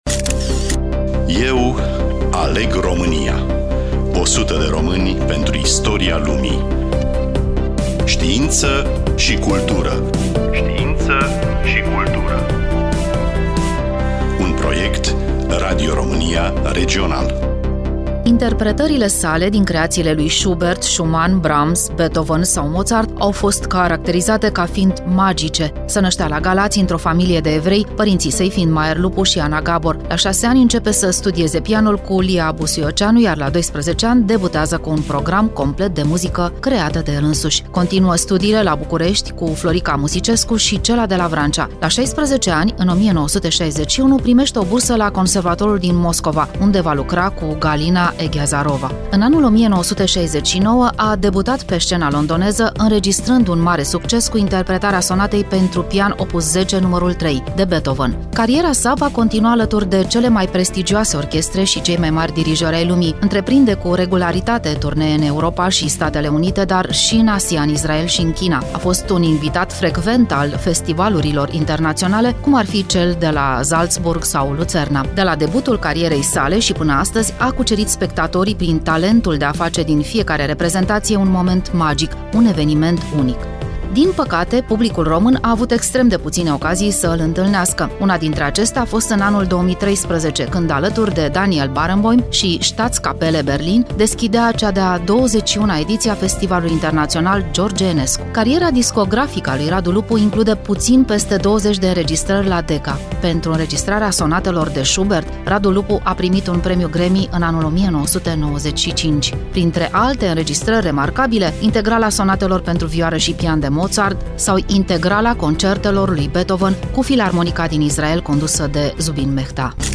Studioul: Radio Romania Constanţa